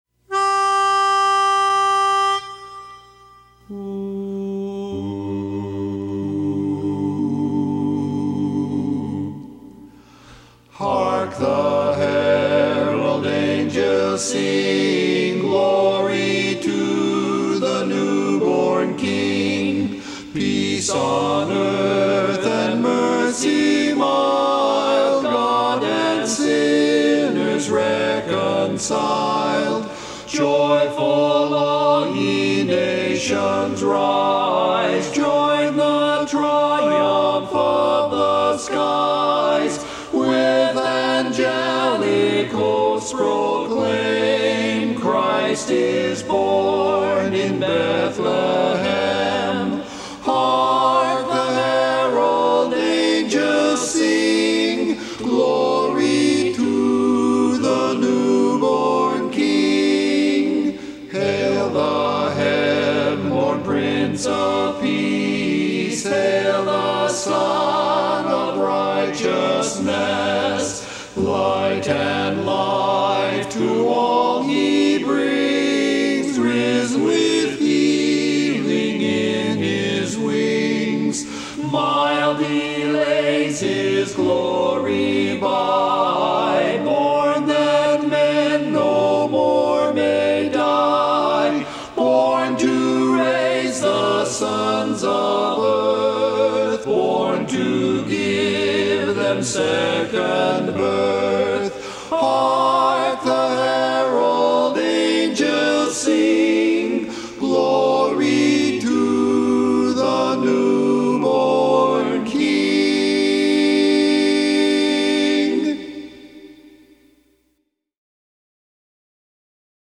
Barbershop
Bari